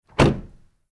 Cerrar la puerta de un coche Triumph con un portazo